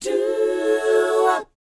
DOWOP B GU.wav